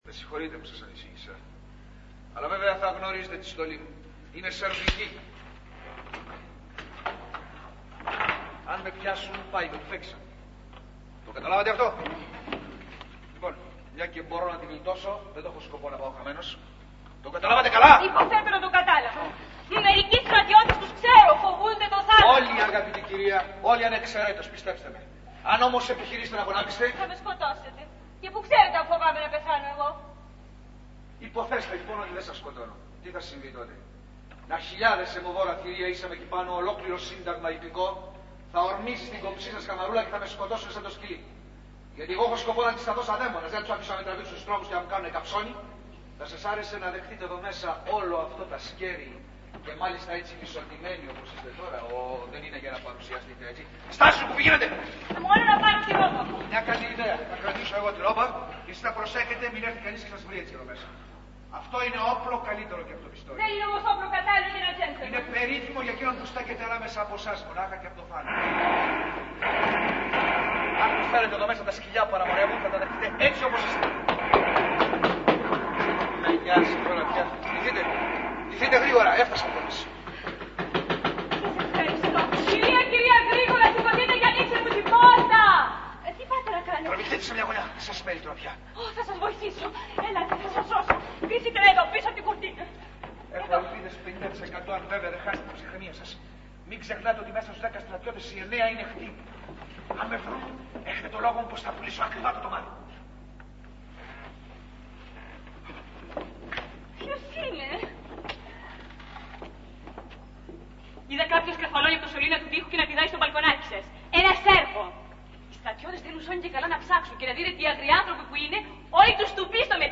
Ηχογράφηση Παράστασης
Αποσπάσματα από την παράσταση
sound 2'31'', Ράινα-Άγνωστος-Λούκα-Κατερίνα-Ρώσος αξιωματικός